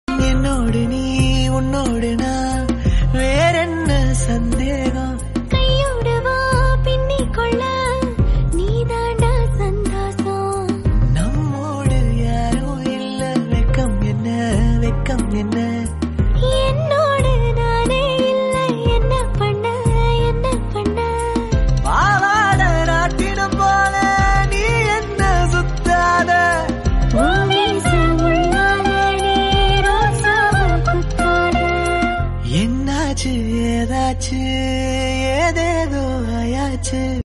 Trending Tamil BGM ringtone for mobile.